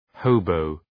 Προφορά
{‘həʋbəʋ}